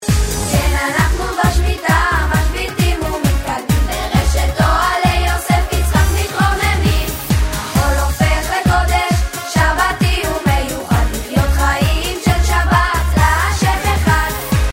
שיר